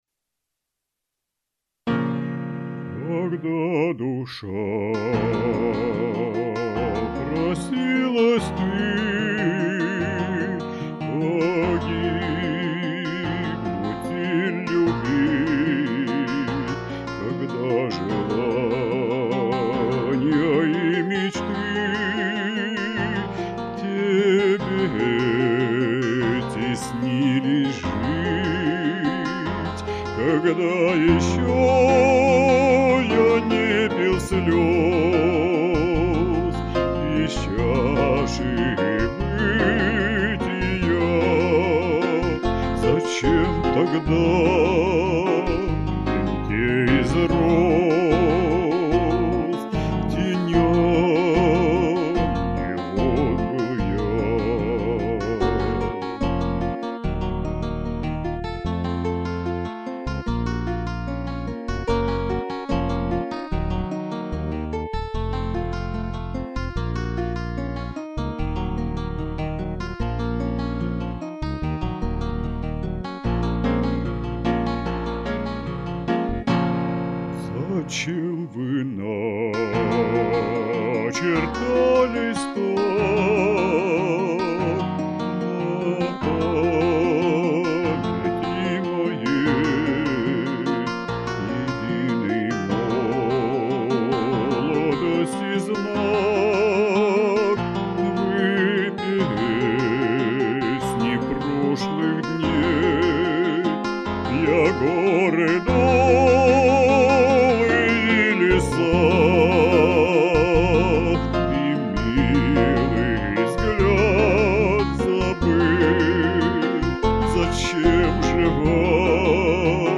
Оба исполнения великолепны ,но ,наверное ,женский романс мне ближе) Браво!!!
какая именно мне ближе....Страстно-цыганская, с речитативами, с выразительными въездами
это романс цыганский